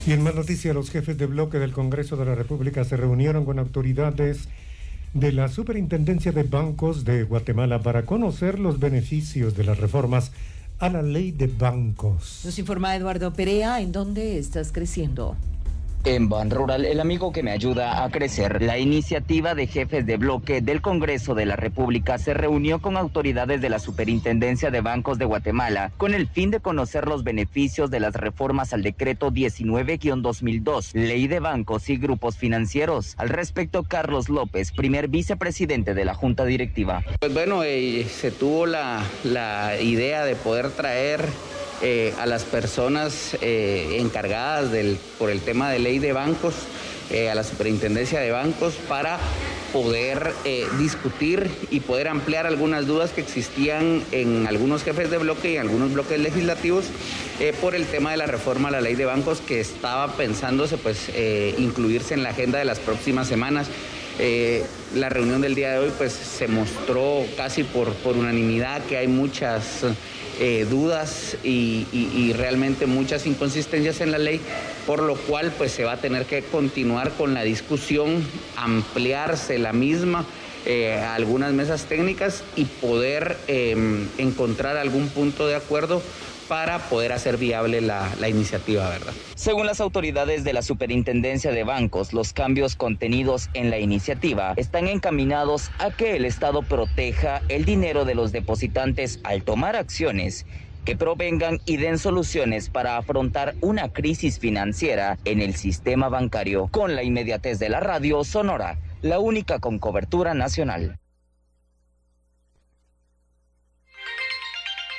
Noticias Iniciativa de Ley 5157